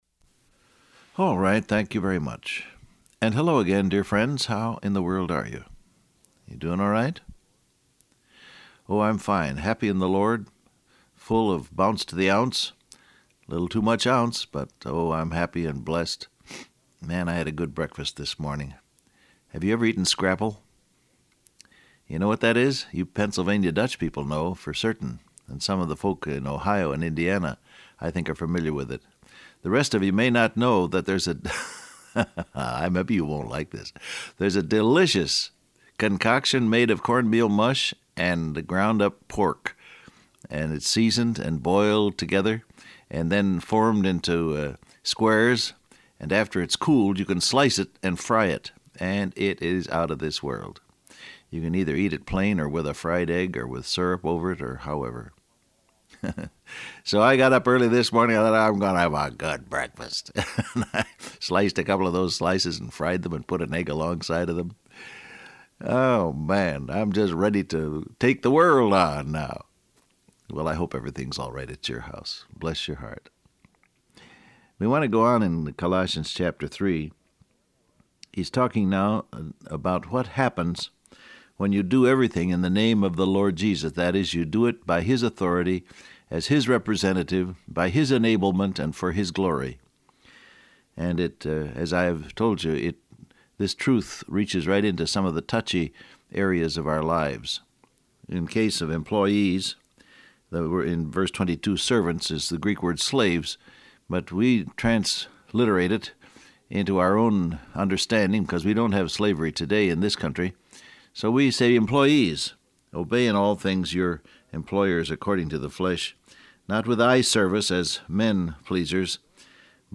Download Audio Print Broadcast #1922 Scripture: Colossians 3:18-24 , Colossians 3:4, Philippians 2:1-5 Transcript Facebook Twitter WhatsApp Alright, thank you very much.